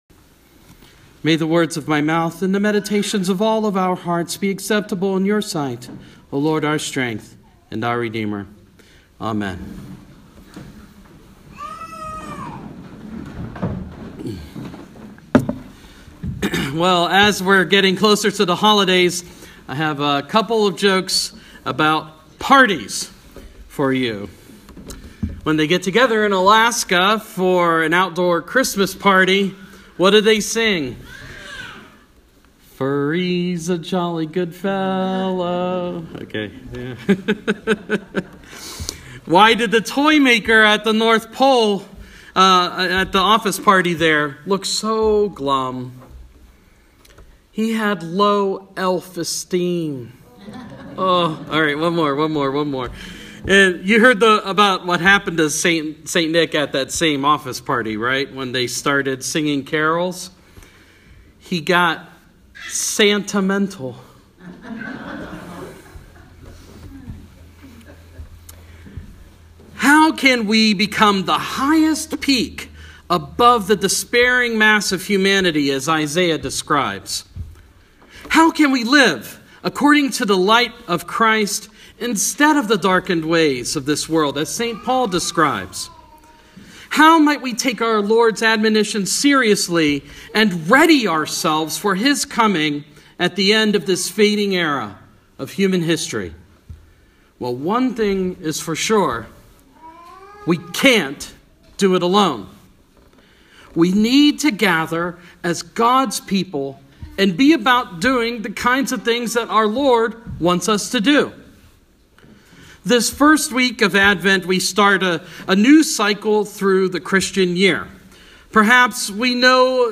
Sermon – First Sunday in Advent